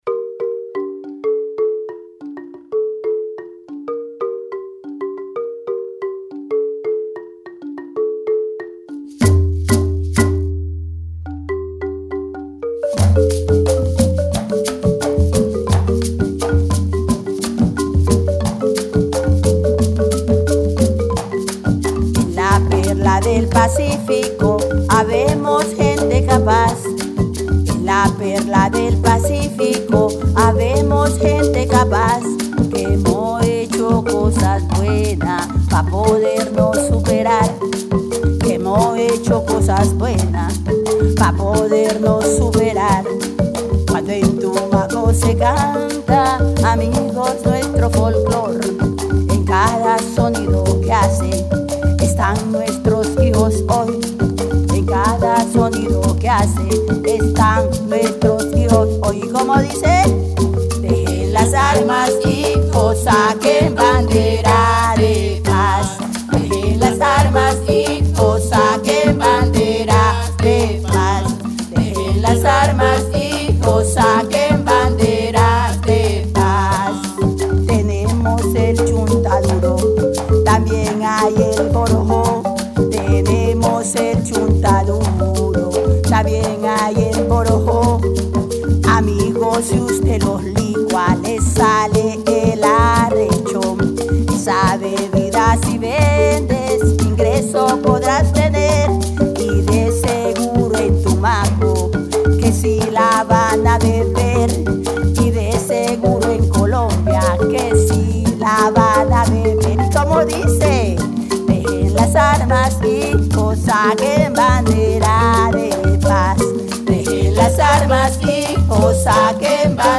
Canción
cununo, bordón, marimba, requinto, bombo y guasá.
coros.